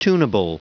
Prononciation du mot tunable en anglais (fichier audio)
Prononciation du mot : tunable